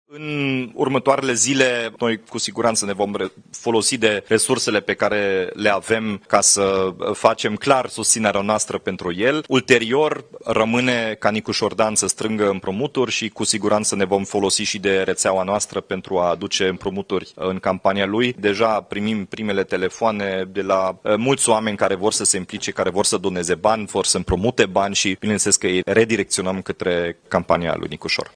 USR votează luni, 5 mai, dacă îl va susține pe Nicușor Dan în turul II în alegerile prezidențiale, a anunțat președintele interimar al partidului, Dominic Fritz – la o conferință de presă.